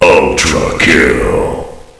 flak_m/sounds/announcer/int/ultrakill.ogg at trunk